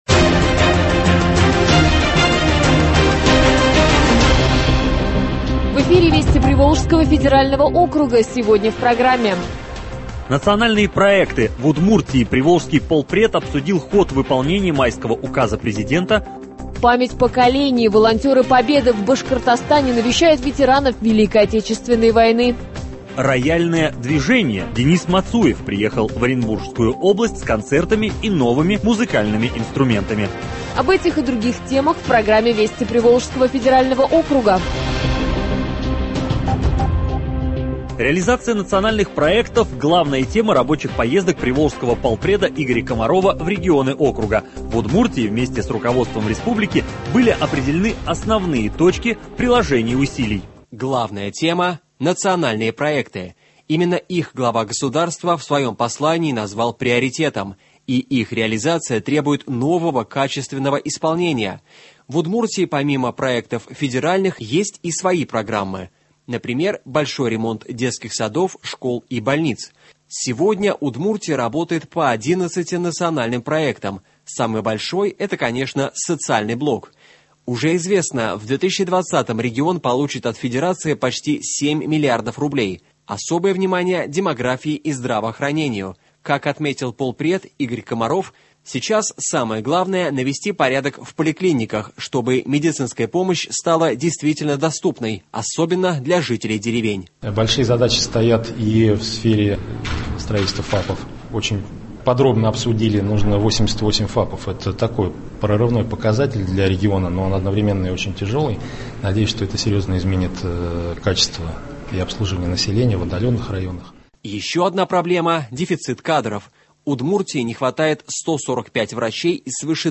Радиоверсия тележурнала, рассказывающего о событиях в регионах ПФО.